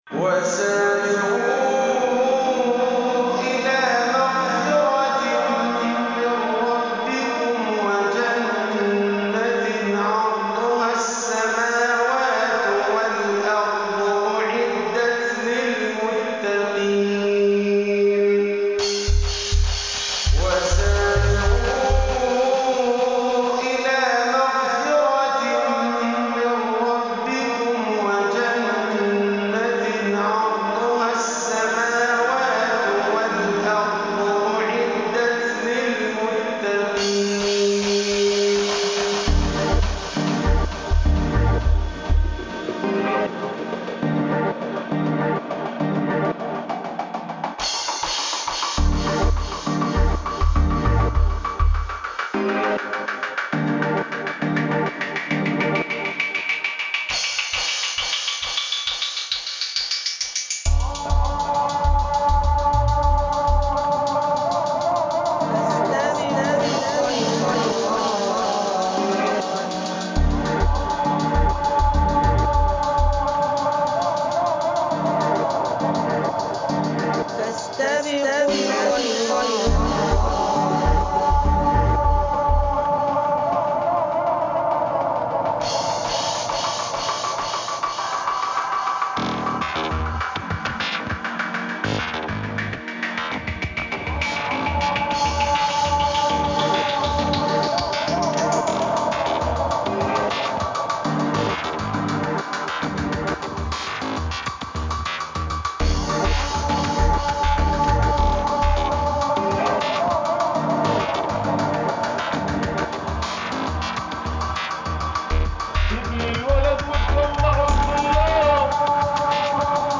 ambient dub sounds
Jerusalem Mount of Olives reimagined